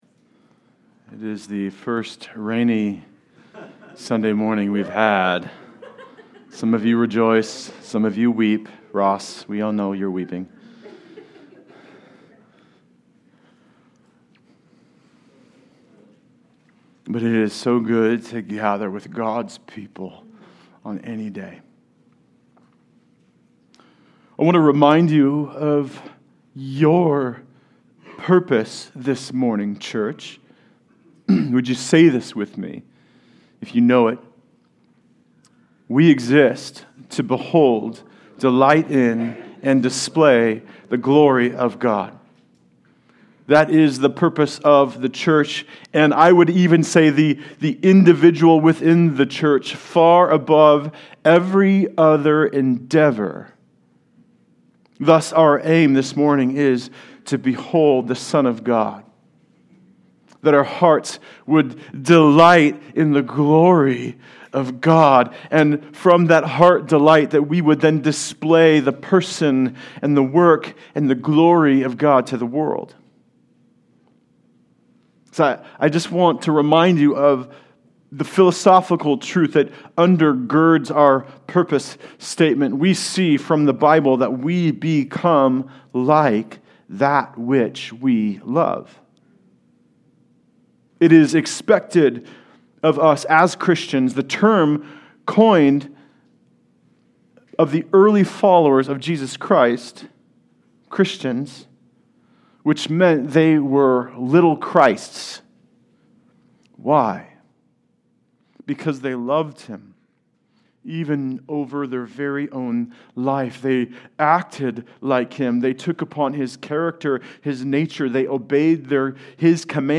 Good News for All People Passage: Luke 6:37-49 Service Type: Sunday Service Related « From Sinners to Sons of the Most High Lord?